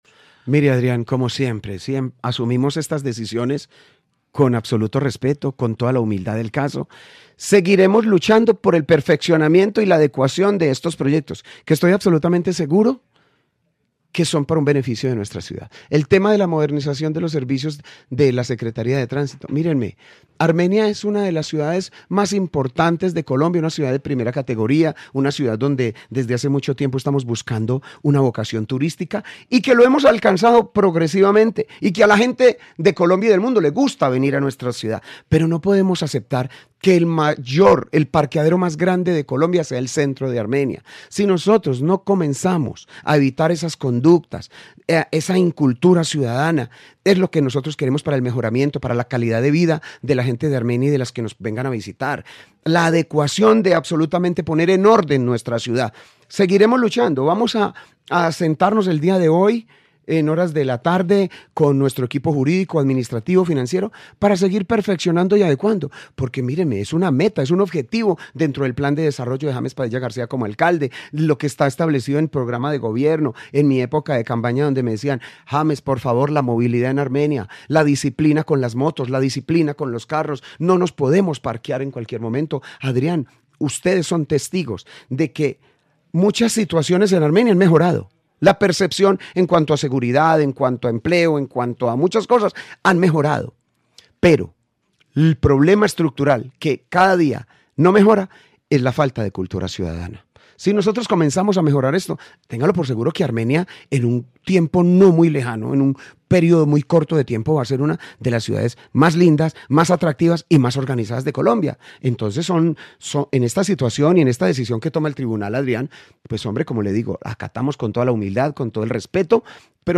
James Padilla, alcalde de Armenia
En el noticiero del mediodía de Caracol Radio Armenia tuvimos como invitado al alcalde de Armenia, James Padilla García que entre otros temas habló del fallo del Tribunal Administrativo del Quindío que invalidó el proyecto de acuerdo que pretendía concesionar los servicios de la Secretaria de Tránsito y Transporte de Armenia, Setta y es la segunda oportunidad que el TAG invalida dicho acuerdo.